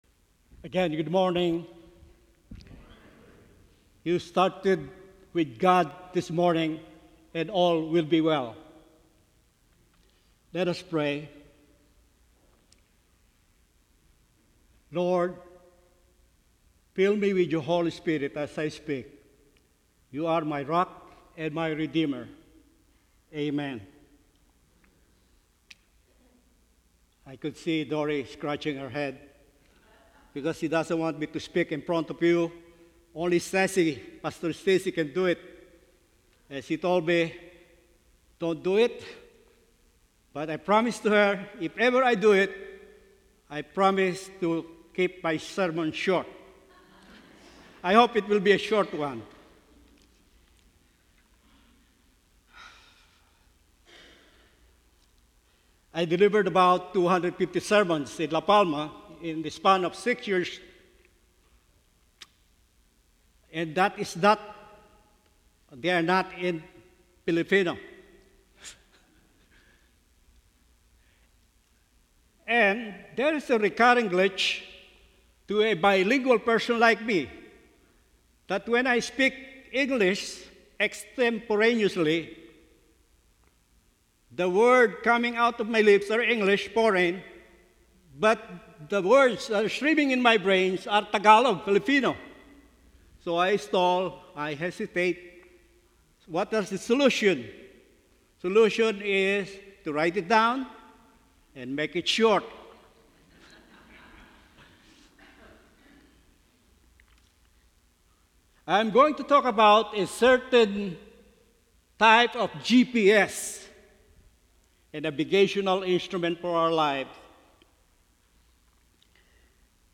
9:30 AM Worship